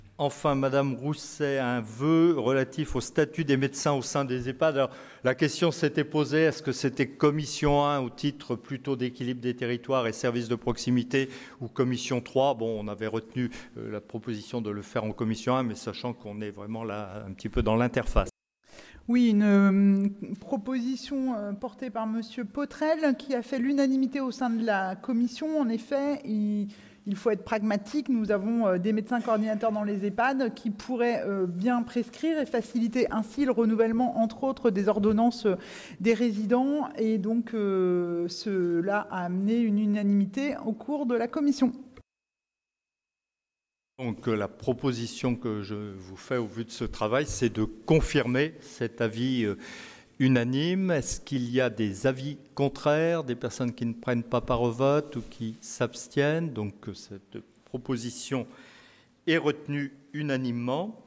Libellé de la politique Aménagement et développement des territoires Nature Assemblée départementale Intervenant Jean-Luc Chenut